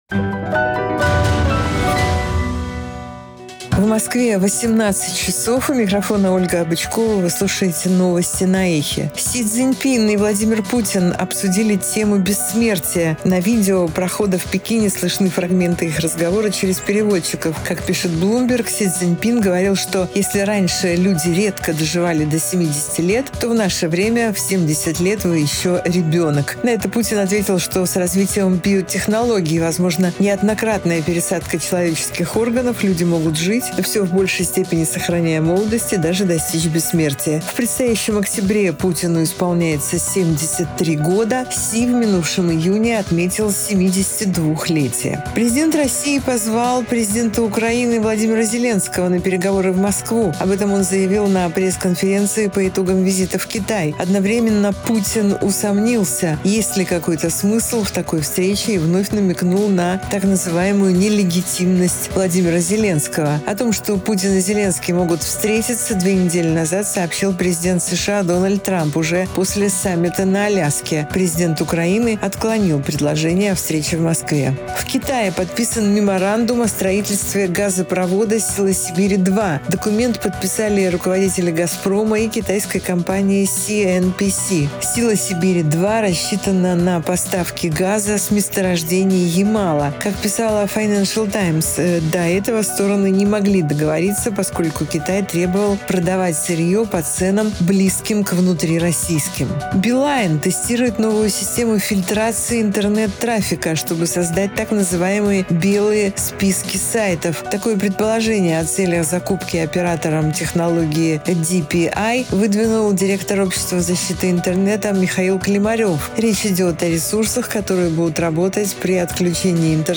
Слушайте свежий выпуск новостей «Эха»
Новости 18:00